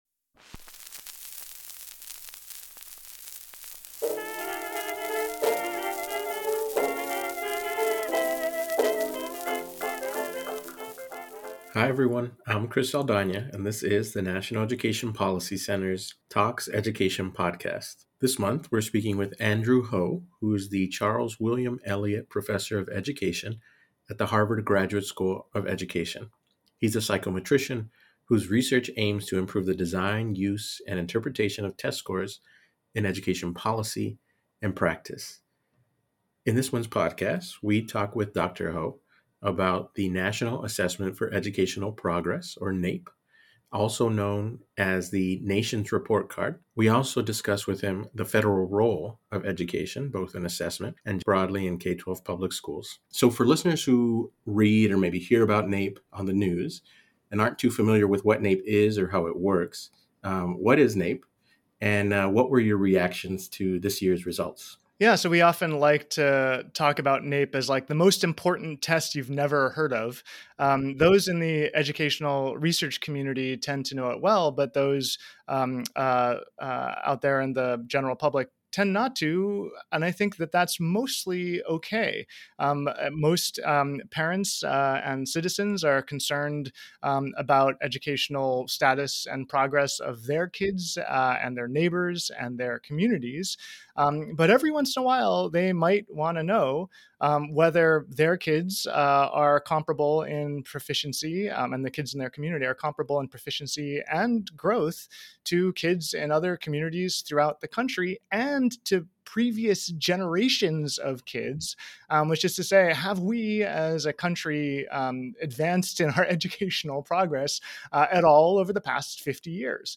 NEPC Talks Education: An Interview